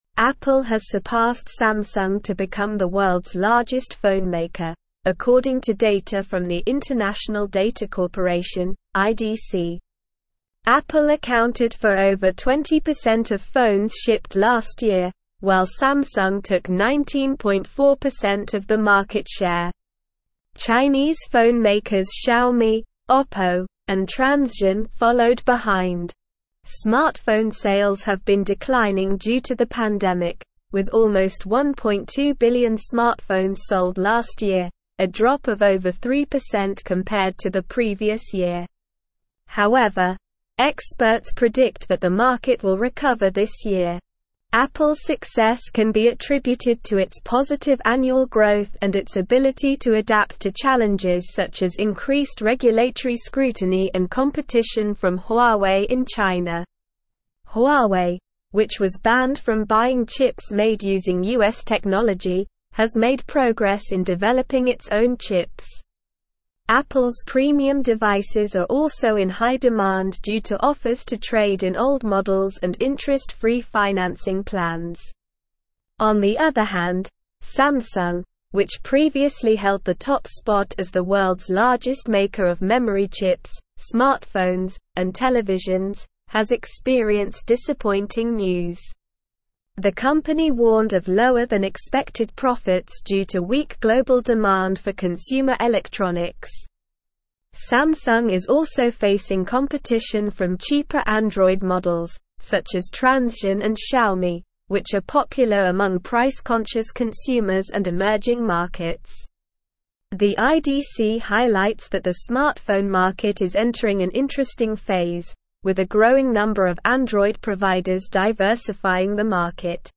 Slow
English-Newsroom-Advanced-SLOW-Reading-Apple-Surpasses-Samsung-as-Worlds-Largest-Phonemaker.mp3